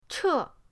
che4.mp3